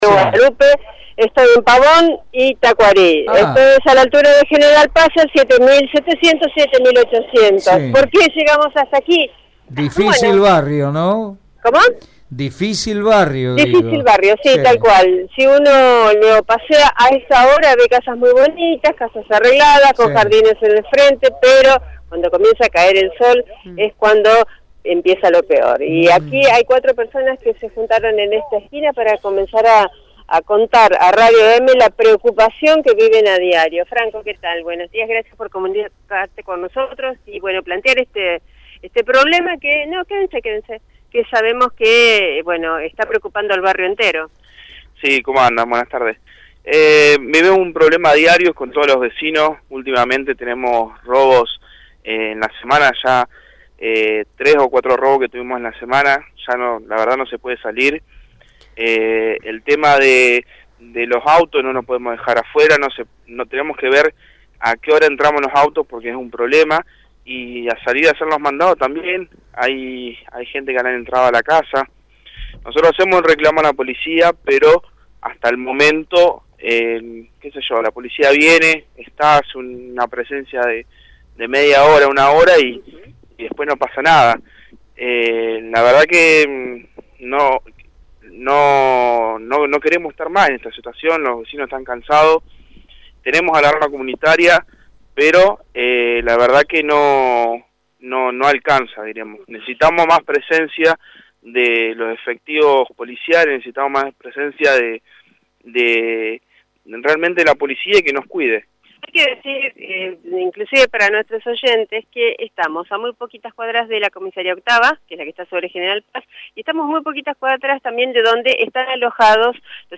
Los vecinos de unos de los barrios más grandes y residenciales de la ciudad de Santa Fe se manifestaron en horas de la siesta contra la inseguridad. El móvil de Radio Eme estuvo presente entre las personas que viven en el lugar y uno por uno contó su mala experiencia con ladrones.